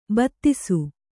♪ battisu